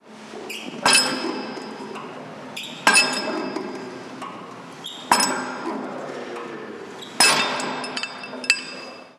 Máquina de pesas 5